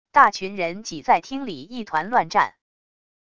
大群人挤在厅里一团乱战wav音频